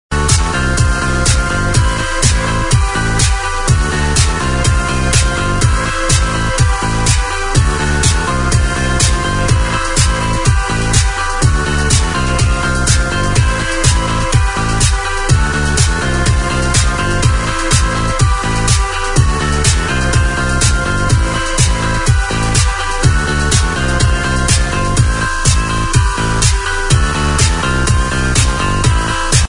HELP ID HOUSE